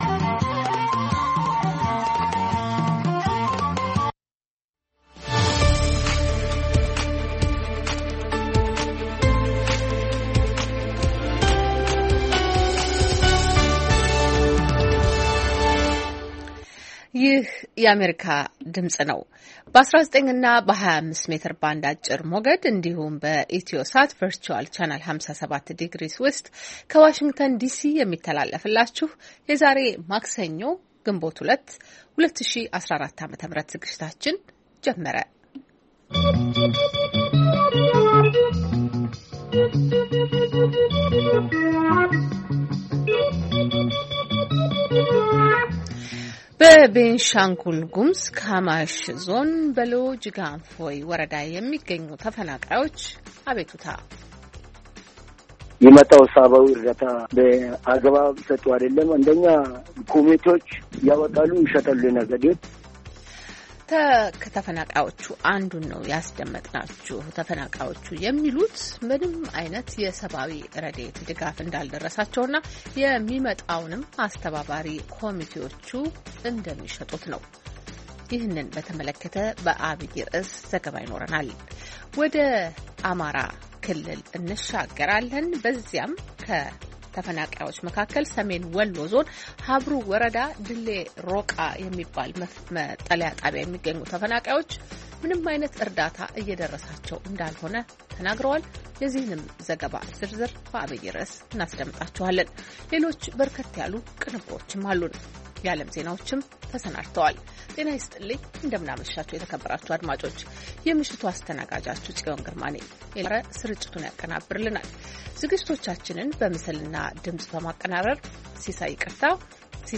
ማክሰኞ፡- ከምሽቱ ሦስት ሰዓት የአማርኛ ዜና
ቪኦኤ በየዕለቱ ከምሽቱ 3 ሰዓት በኢትዮጵያ ኣቆጣጠር ጀምሮ በአማርኛ፣ በአጭር ሞገድ 22፣ 25 እና 31 ሜትር ባንድ የ60 ደቂቃ ሥርጭቱ ዜና፣ አበይት ዜናዎች ትንታኔና ሌሎችም ወቅታዊ መረጃዎችን የያዙ ፕሮግራሞች ያስተላልፋል። ማክሰኞ፡- ሐኪሙን ይጠይቁ፣ ንግድ፣ ምጣኔ ኃብትና ግብርና